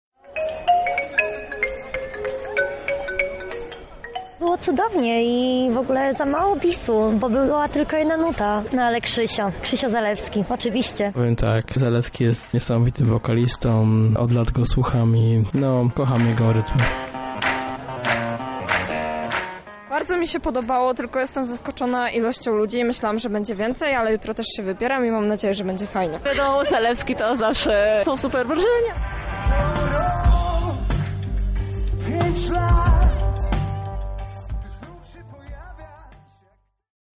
Na miejscu była nasza reporterka